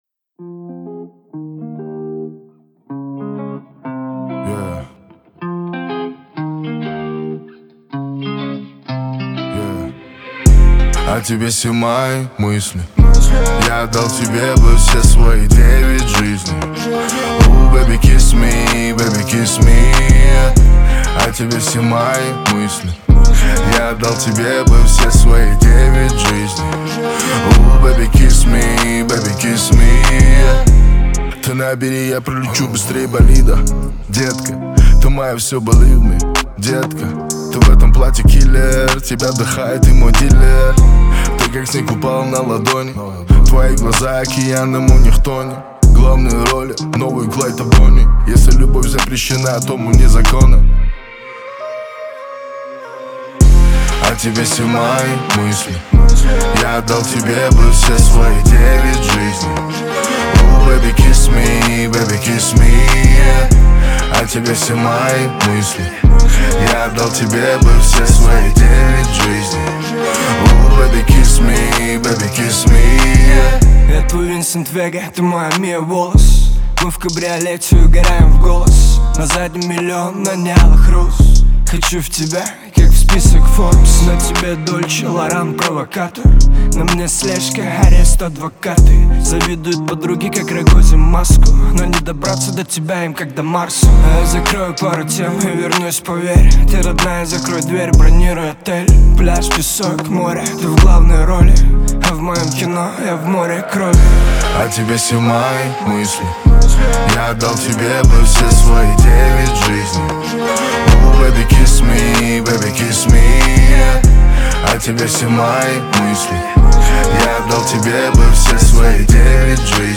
это динамичная и романтичная композиция в жанре поп